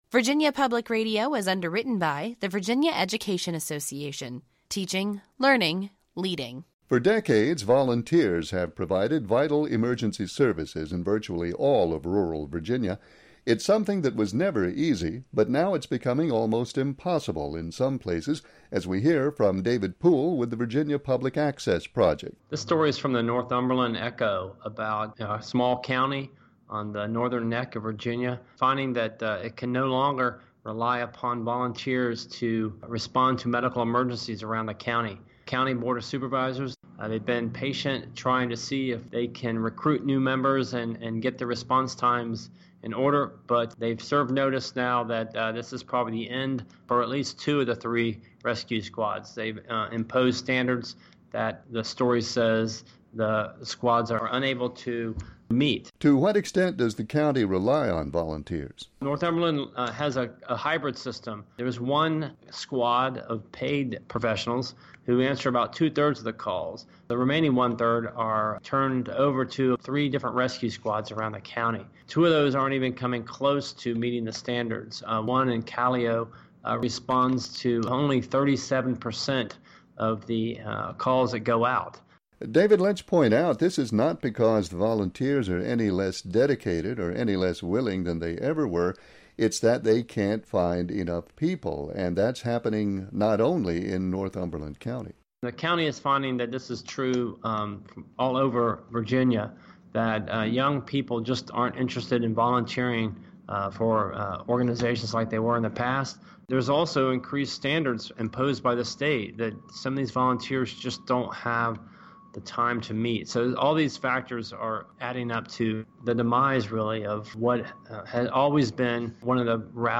and has this report. https